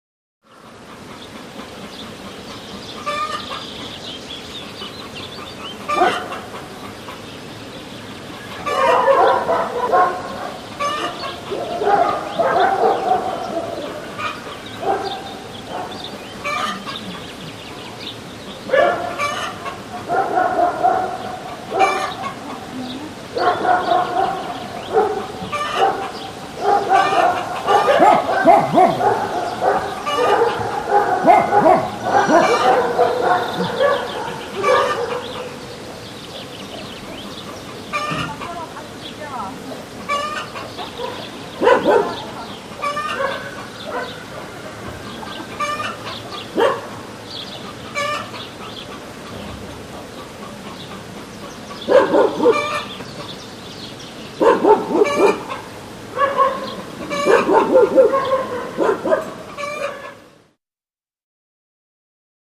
Village Morning 02; Many Dogs Barking, Light Chicken Clucks, Light Birdsong And Distant Wind In Trees